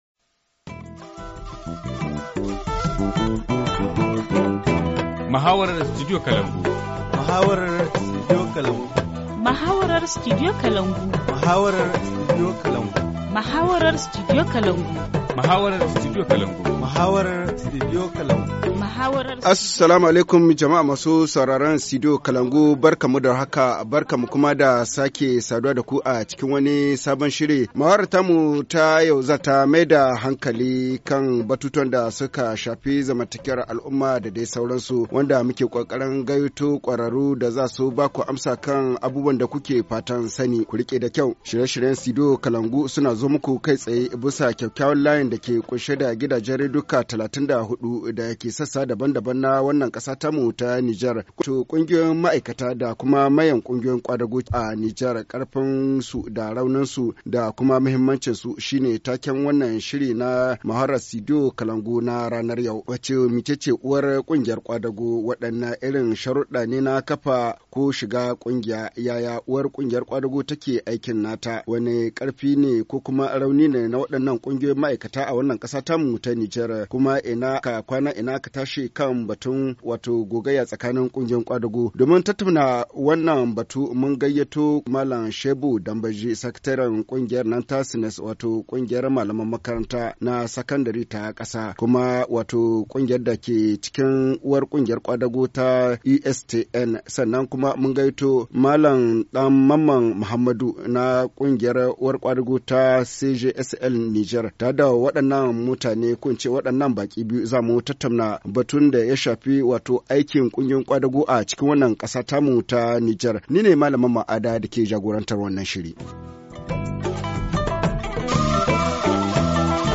Forum Haoussa 17/03/2018 - Les syndicats et les centrales syndicales au Niger : forces, faiblesses et perspectives - Studio Kalangou - Au rythme du Niger